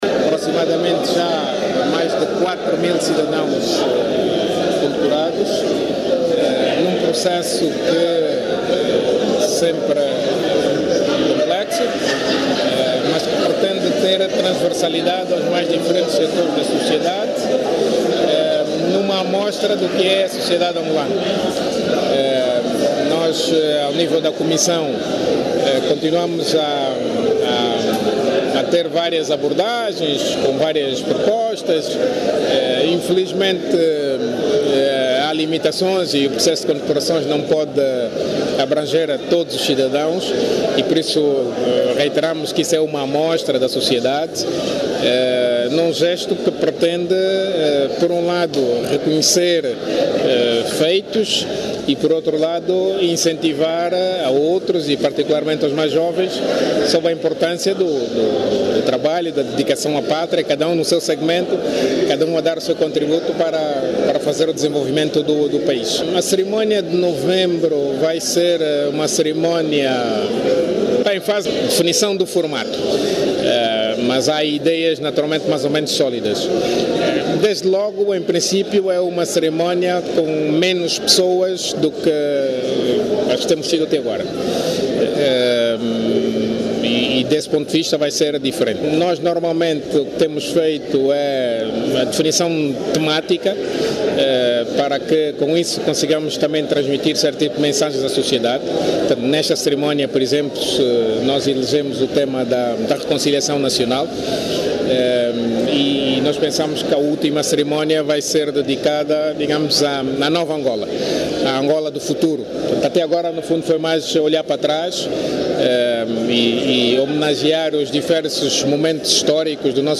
O Ministro de Estado e Chefe da Casa Civil do Presidente da República, Adão de Almeida, anunciou que já foram condecorados mais de 4 mil cidadãos. Adão de Almeida, fala também como será a cerimónia comemorativa do 11 de Novembro.